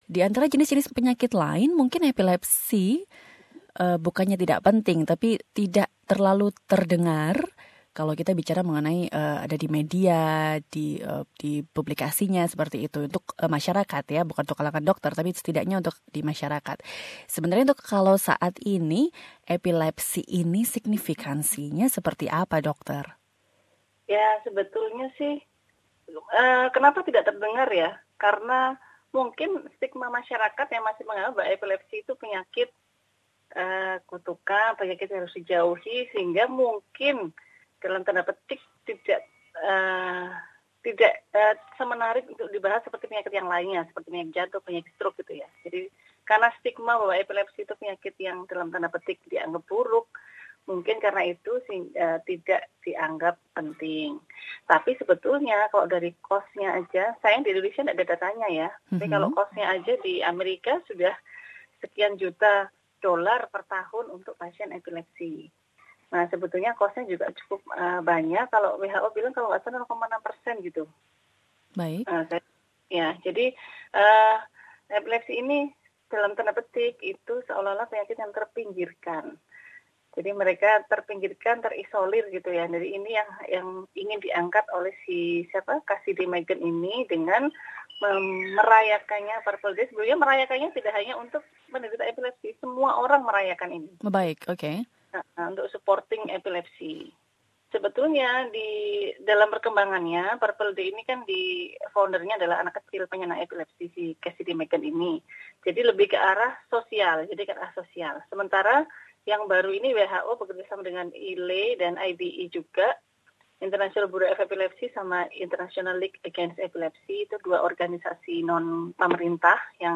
SBS Radio speaks to a neurologist